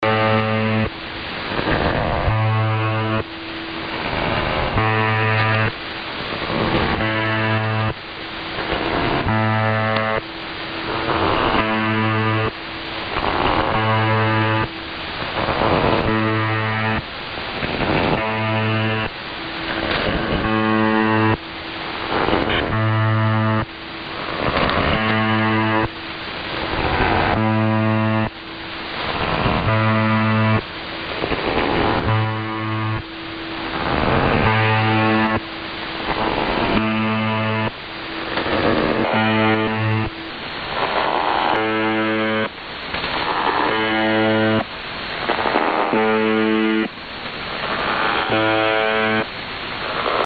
Вот только что заслушивался буззером на ресивере))))))) Вложения websdr_recording_start_2023-08-03T22_01_31Z_4625.0kHz.mp3 websdr_recording_start_2023-08-03T22_01_31Z_4625.0kHz.mp3 622,5 KB · Просмотры